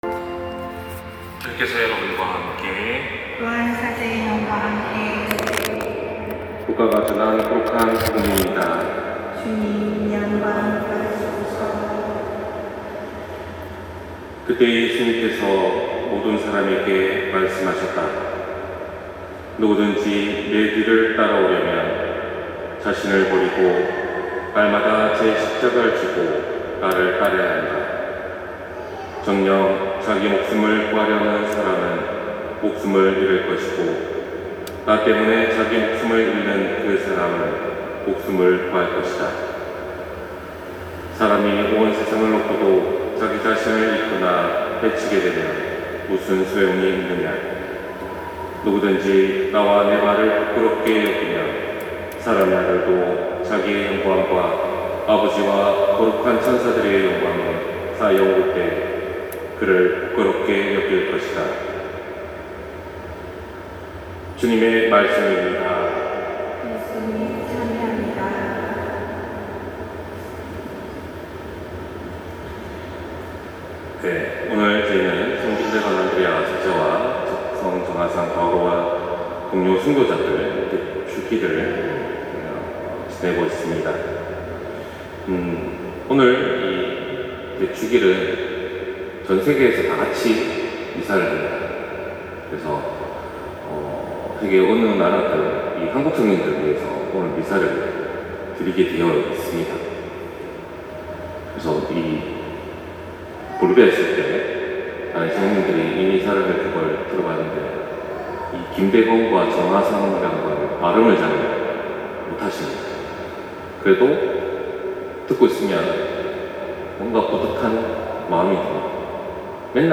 250920 신부님 강론말씀